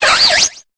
Cri d'Escargaume dans Pokémon Épée et Bouclier.